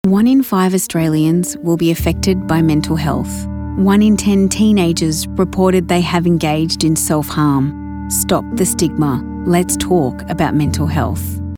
Female
English (Australian)
Adult (30-50)
A friendly, warm and empathic voice with a bubbly and catchy energy.
Corporate